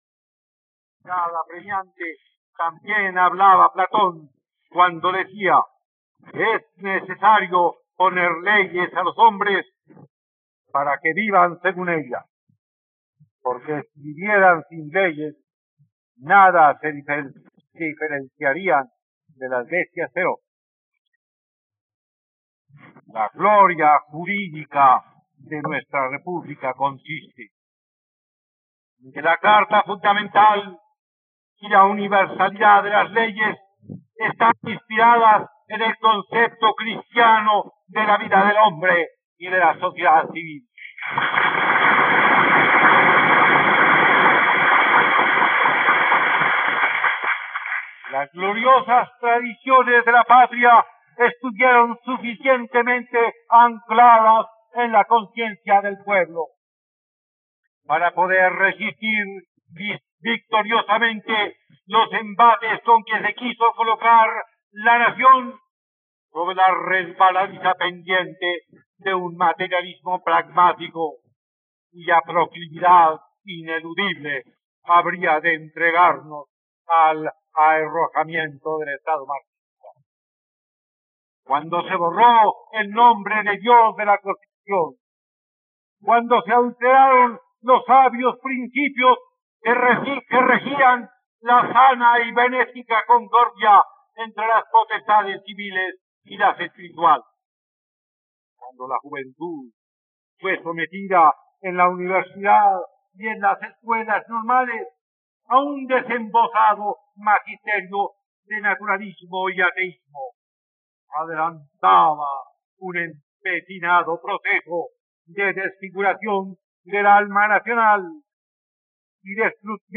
..Escucha ahora el discurso de posesión del presidente Laureano Gómez, el 7 de agosto de 1950, en la plataforma de streaming de los colombianos: RTVCPlay.